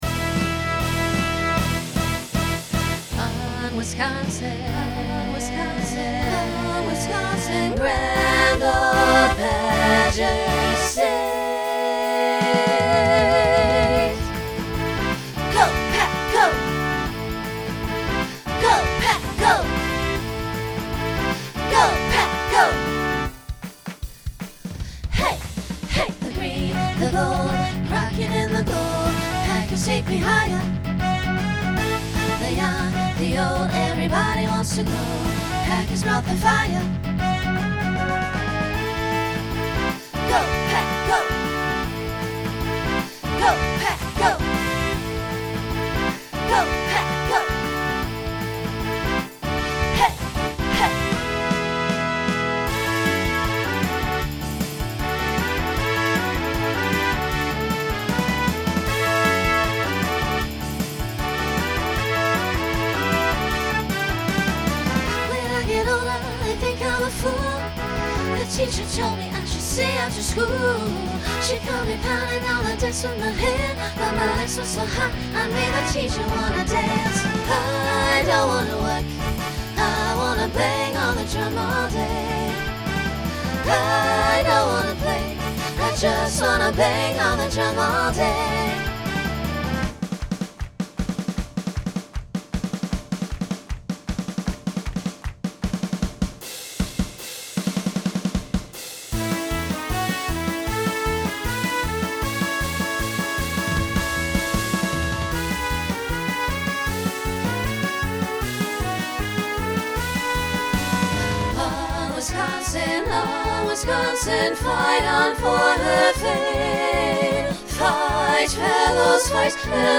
Genre Rock Instrumental combo
Traditional Show Function Closer Voicing SATB